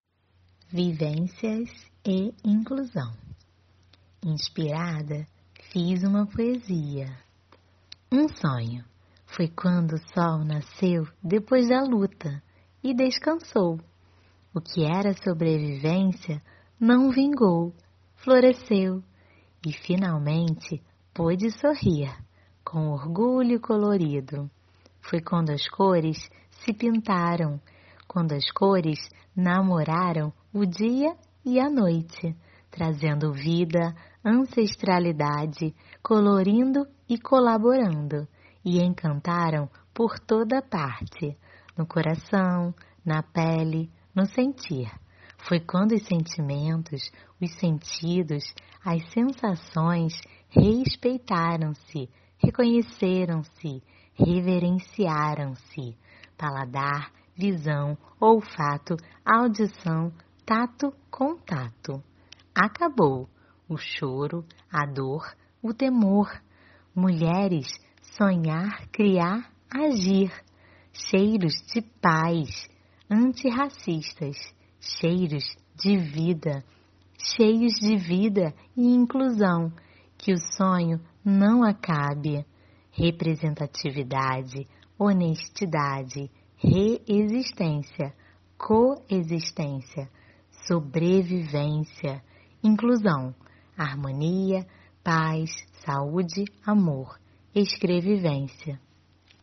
Poesia com voz humana